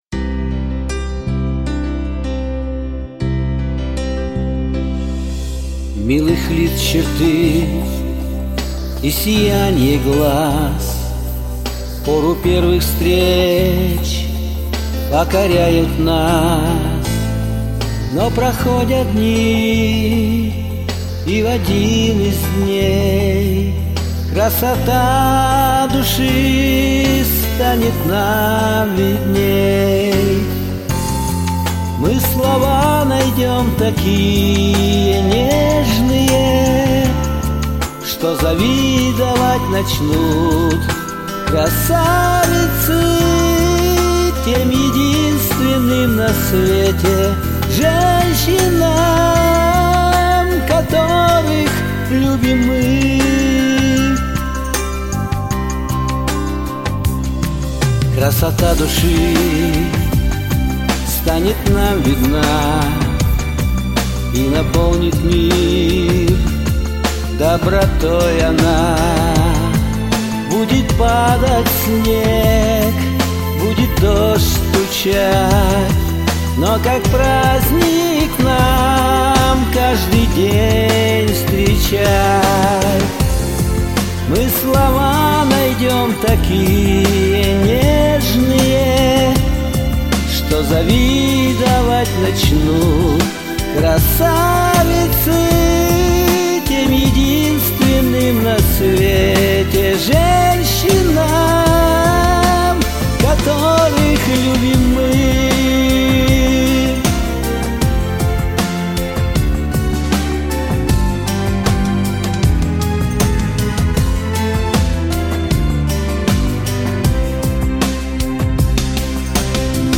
Хорошее такое ретро.